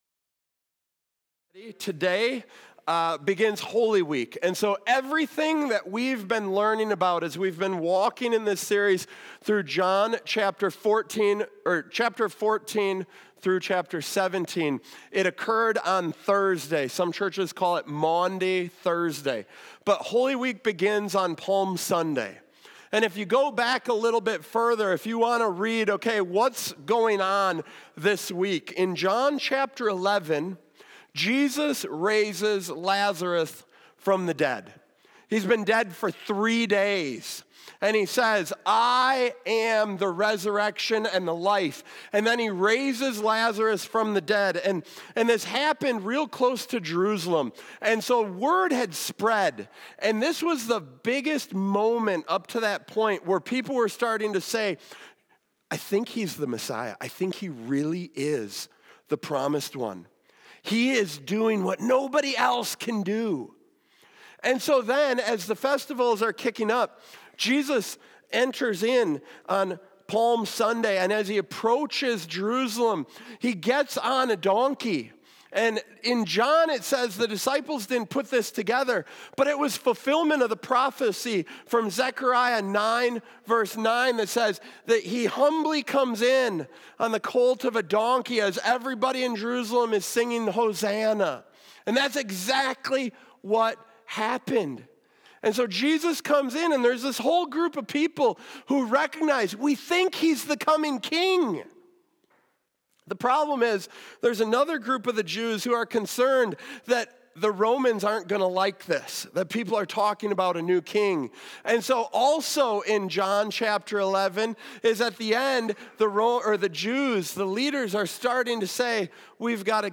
Palm-Sunday-audio.m4a